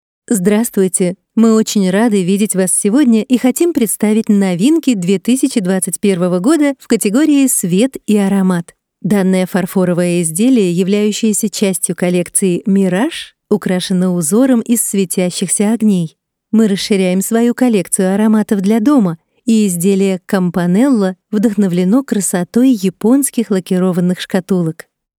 Female
Approachable, Bright, Character, Confident, Conversational, Engaging, Friendly, Natural, Smooth, Upbeat, Versatile, Warm, Witty, Young
English and German with Russian accent
Educational.mp3
Microphone: Audio Technica 4033